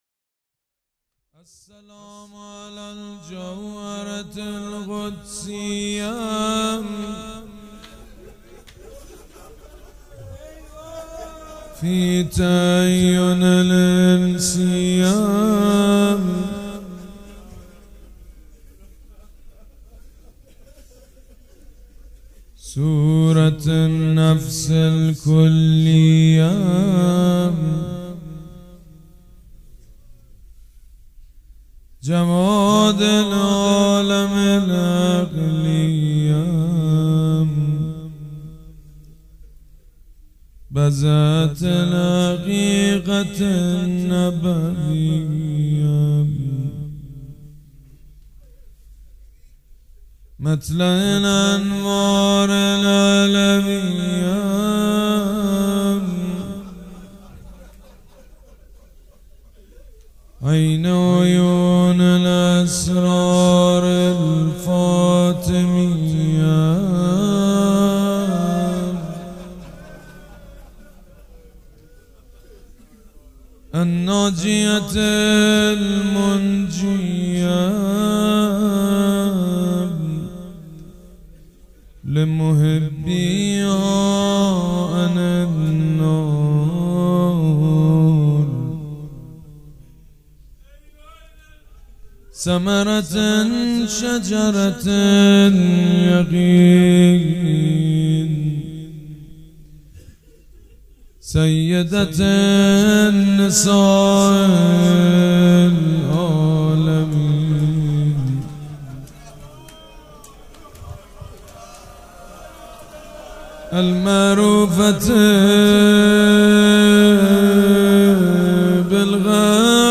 فاطمیه 96 - روضه - مرا ببین و برای سفر